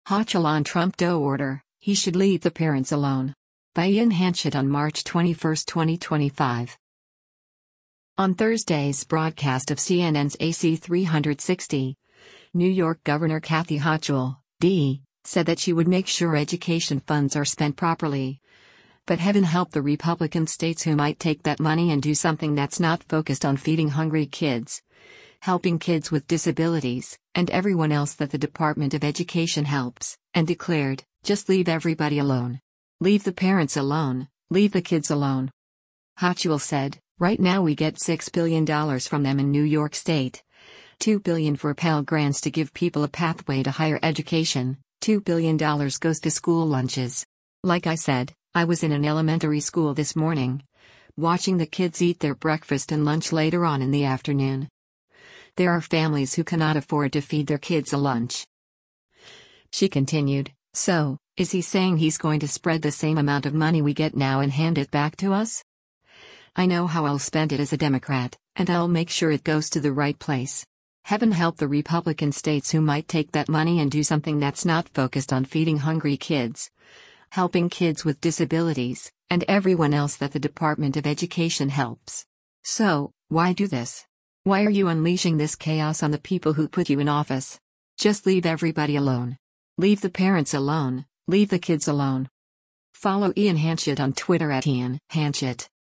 On Thursday’s broadcast of CNN’s “AC360,” New York Gov. Kathy Hochul (D) said that she would make sure education funds are spent properly, but “Heaven help the Republican states who might take that money and do something that’s not focused on feeding hungry kids, helping kids with disabilities, and everyone else that the Department of Education helps.” And declared, “Just leave everybody alone.